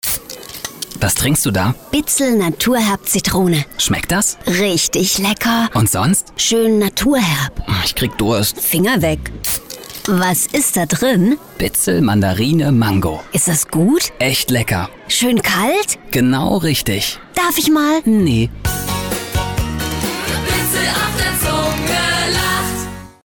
Meine Stimme: Frisch, lebendig, klar, präzise, freundlich, gefühlvoll, warm, verbindlich, seriös.
• Mikrofone: Neumann U87 Ai & TLM 103
DEMO-Werbung.mp3